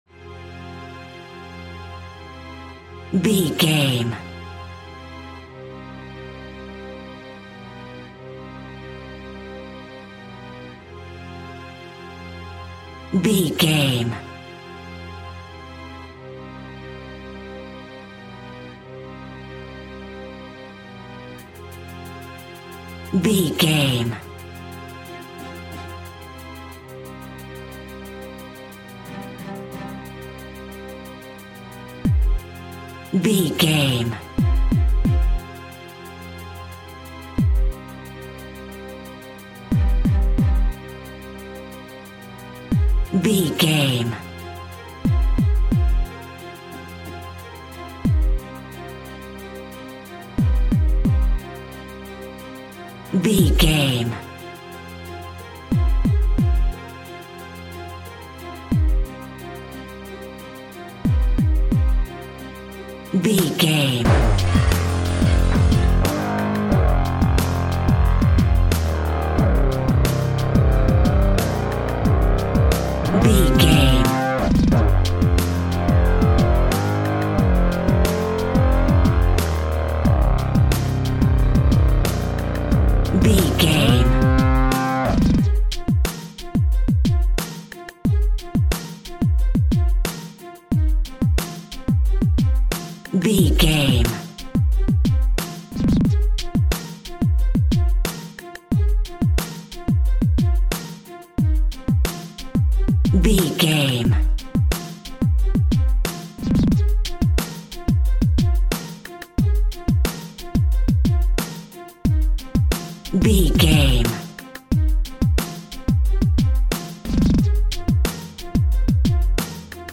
East Coast Hip Hop.
Aeolian/Minor
drum machine
synths